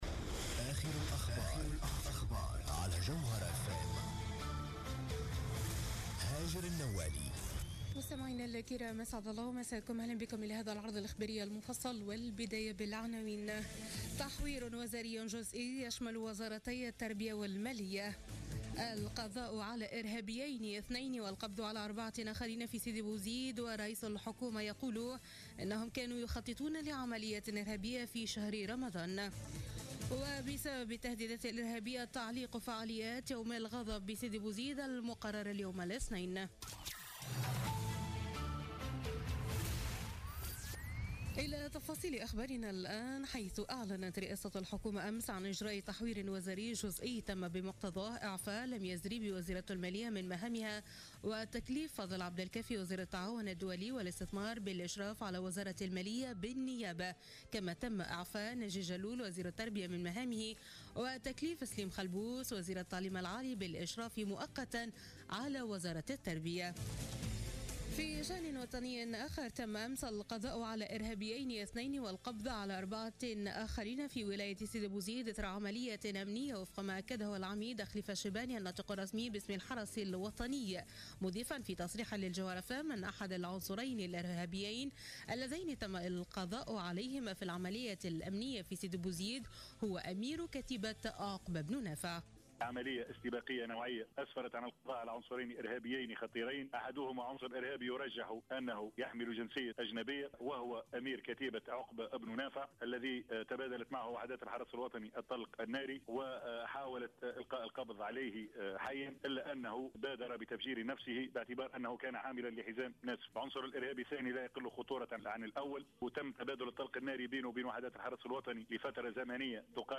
نشرة أخبار منتصف الليل ليوم الإثنين غرة ماي 2017